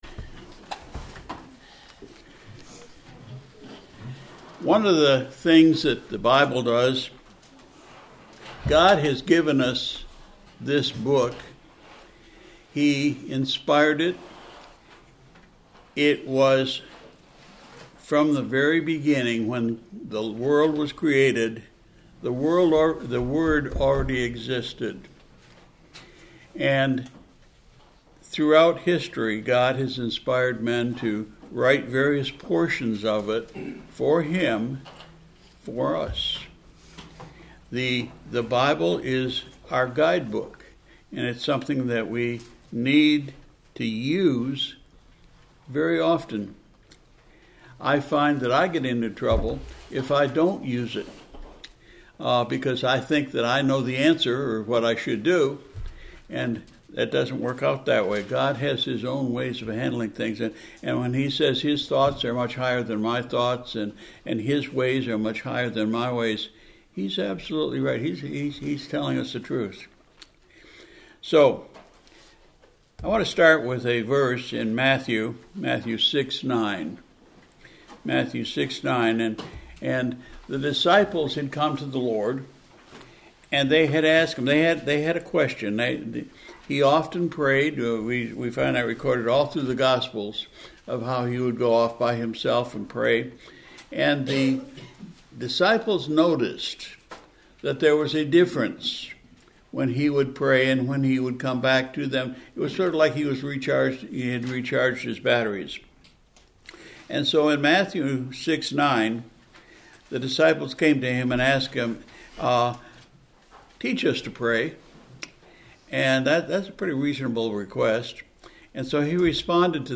March 2, 2025 – PM Service – Instructions from the Lord to the Church